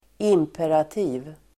Ladda ner uttalet
Uttal: [²'im:perati:v]